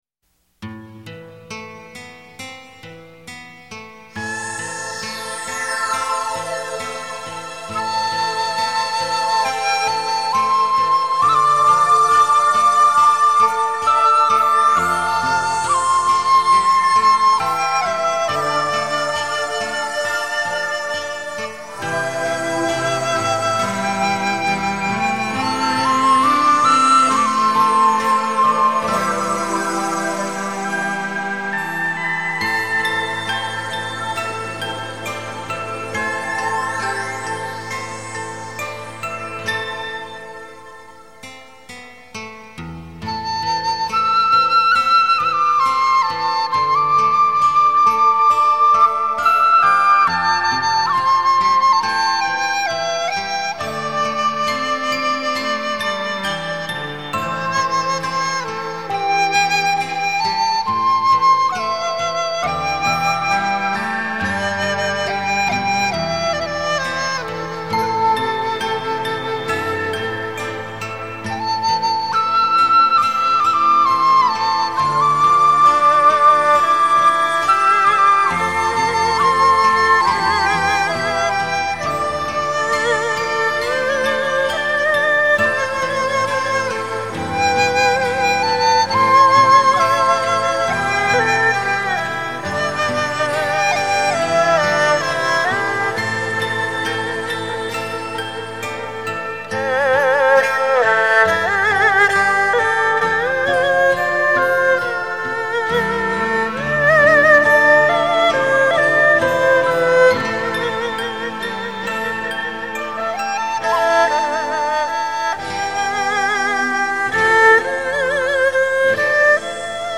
一種淡淡的愁思  油然而生